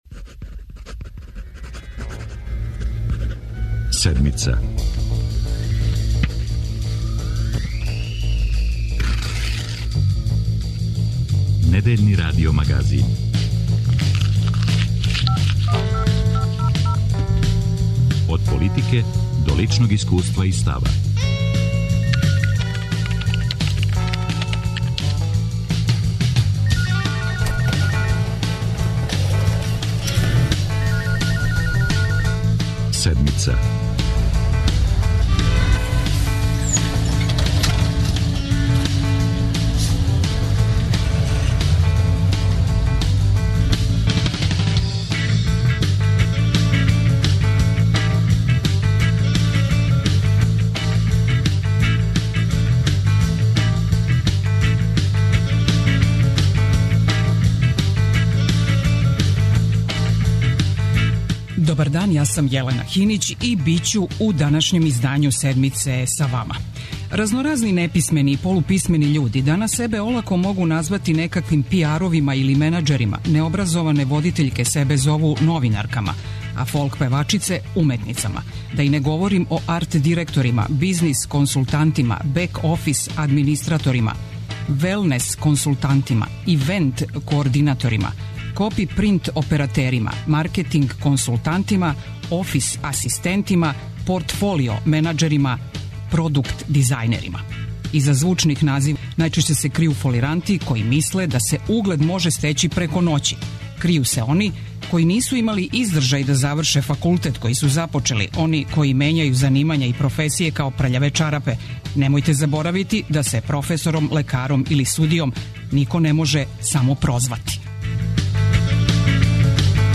Наш саговорник је министар за саобраћај у Влади Србије Милутин Мркоњић, који каже да функцију неће дати! Шта још мисли о реконструкцији владе, позицији СПС у владајућој коалицији и колико ће мере штедње утицати на инфраструктурне пројекте, нека су од питања за министра Мркоњића.